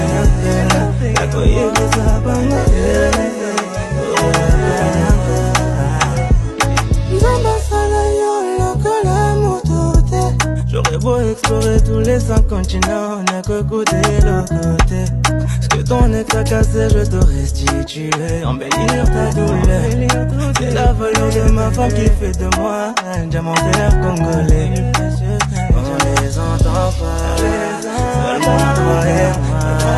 Жанр: Танцевальная музыка
# Modern Dancehall